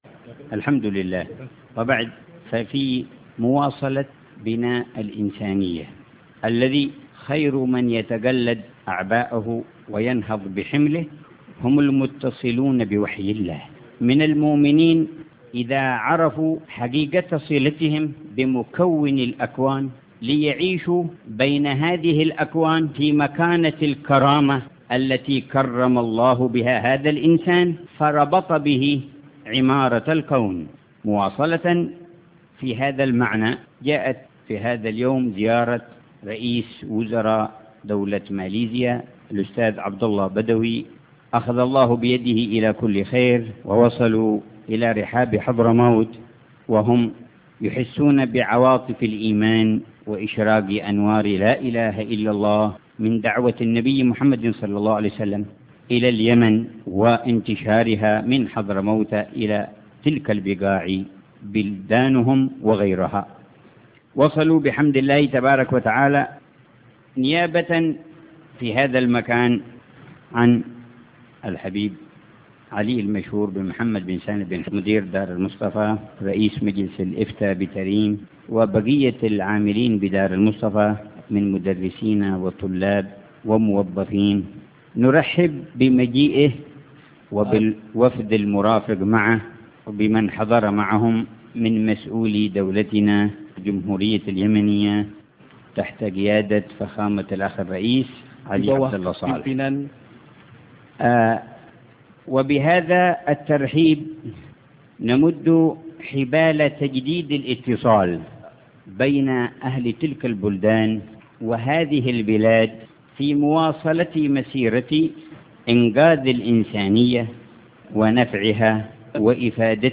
الحبيب عمر يلقي كلمة الترحيب بالضيف الكريم
استمع لكلمة الحبيب عمر كاملة )